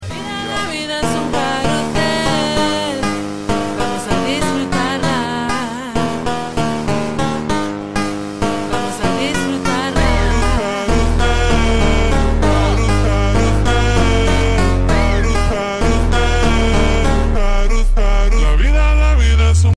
Fpv Kamikaze Drone Vs Artillery sound effects free download